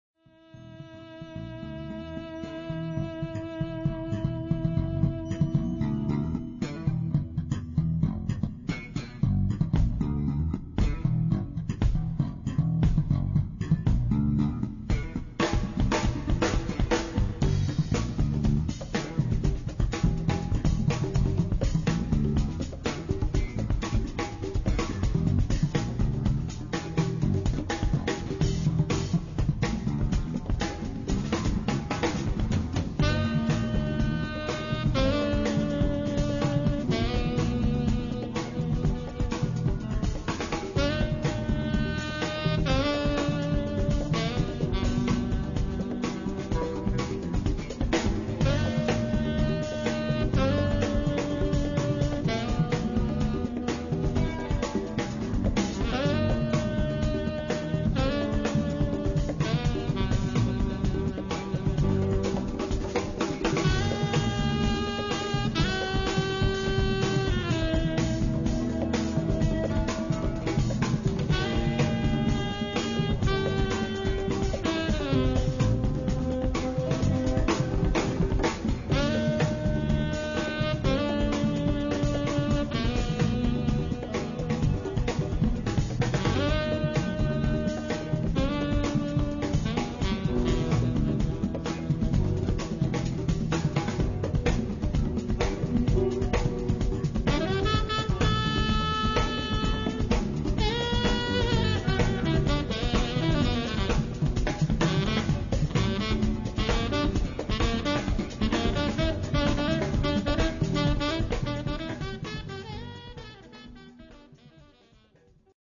Guitars
Bass
Fusing funk, world beat, jazz and joy